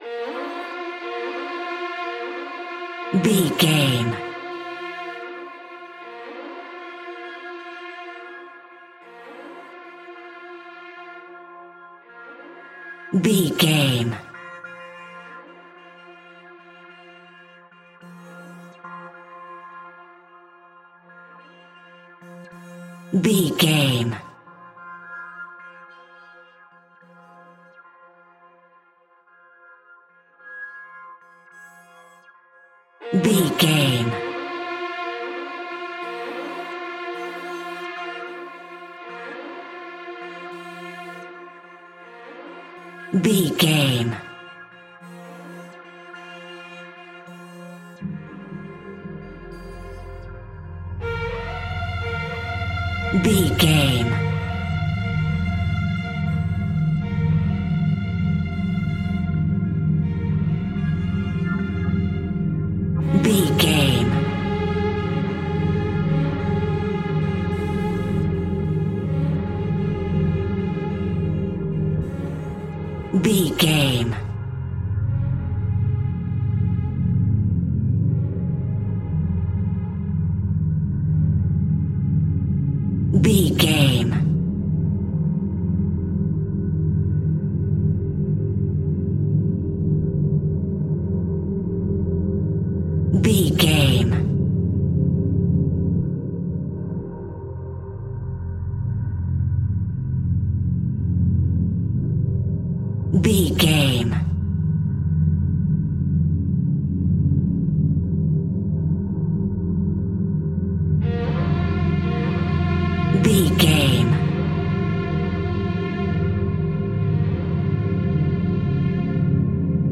Scary Horror Pads.
In-crescendo
Atonal
C#
ominous
eerie
synthesiser
ambience